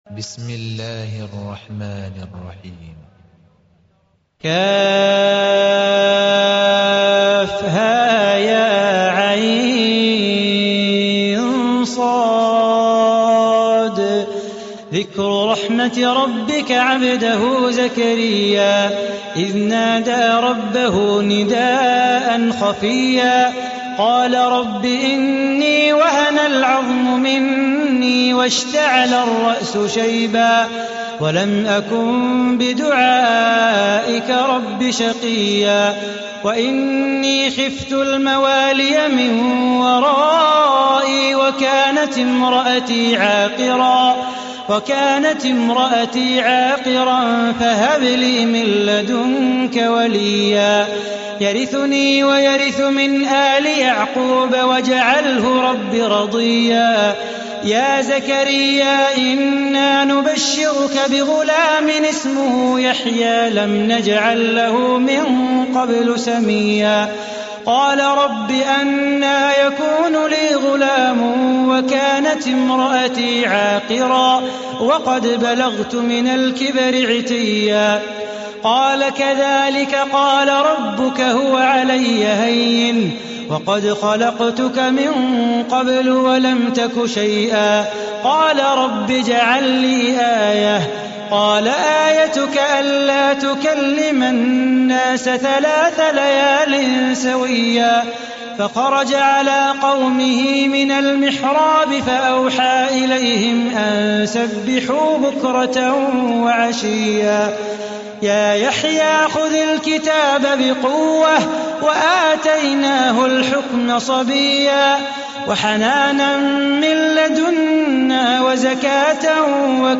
صلاح بو خاطر قارئ إمارتي، ورئيس مجلس إدارة مؤسسة القرآن الكريم والسنة بالشارقة.